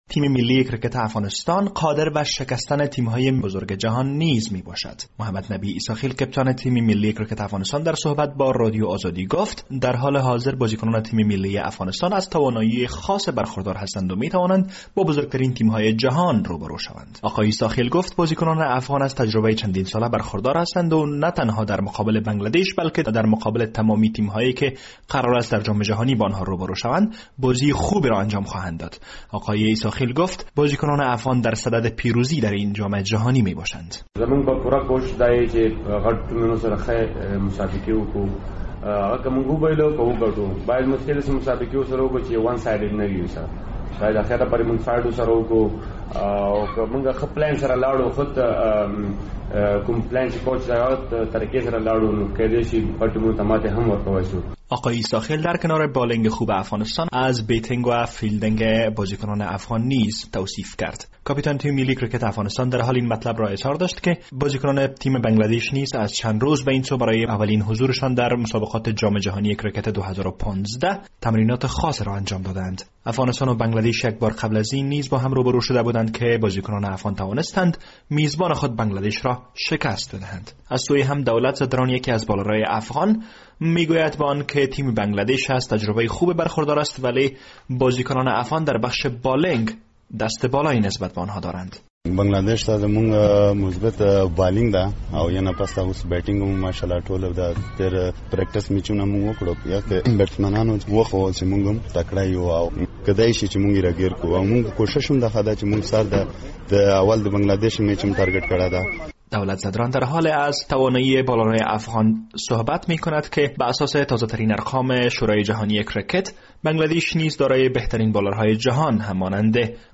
از کانبیرای آسترالیا این گزارش را فرستاده است